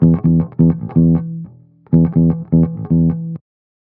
16 Bass Loop E.wav